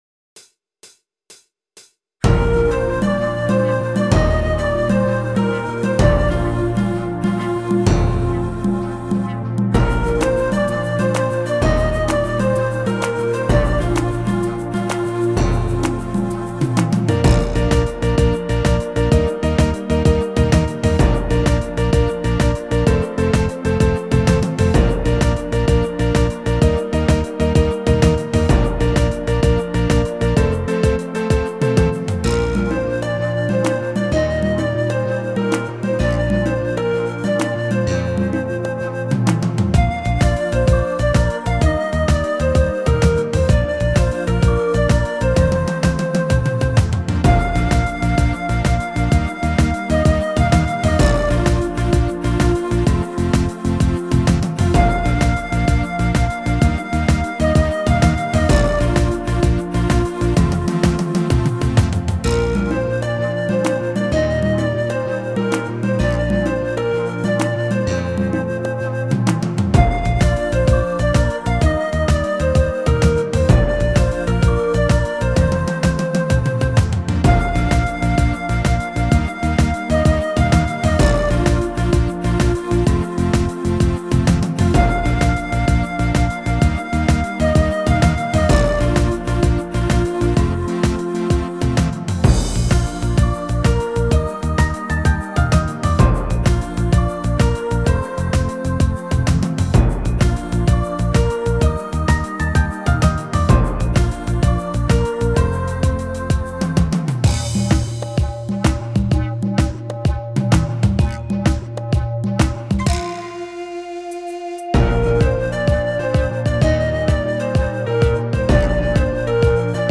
You’ll find it in the Pop/Rock list.